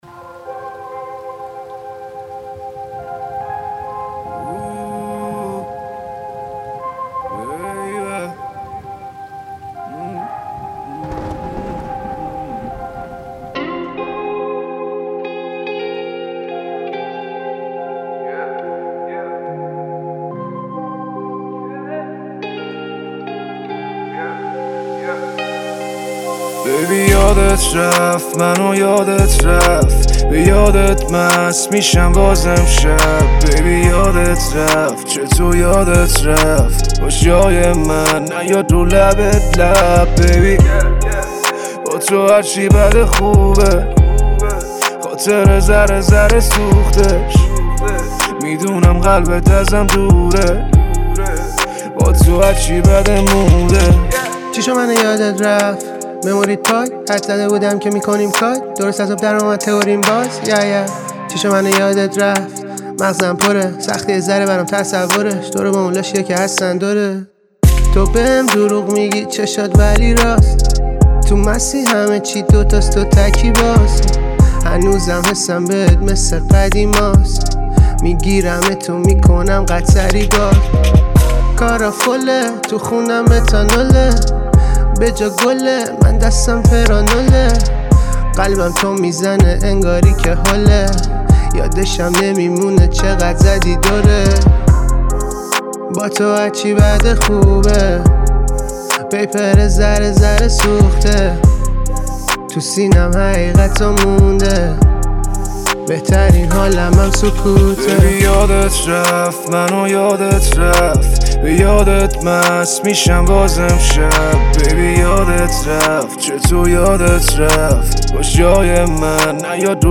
رپر و خواننده
موزیک بغضی جدید